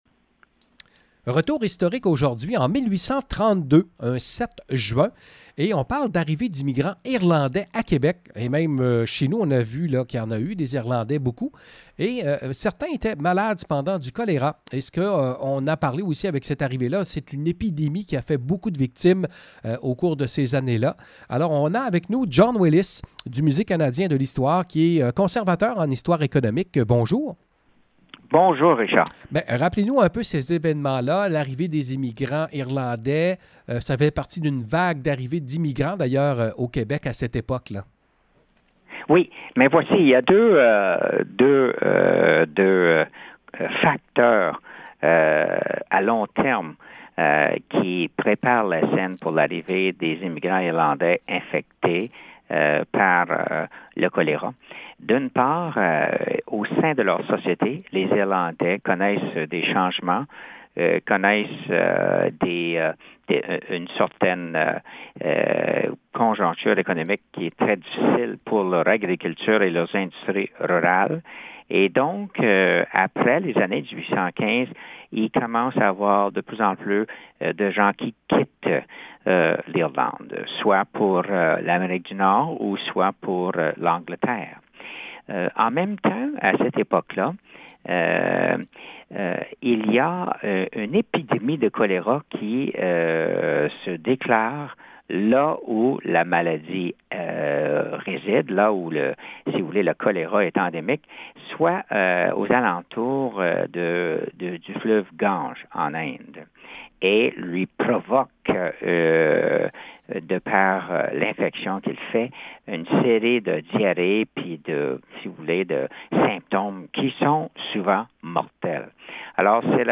En 1832, c’était l’arrivée de plusieurs milliers d’immigrants irlandais au Québec et aussi d’une épidémie de choléra qui allait semer la panique. Entrevue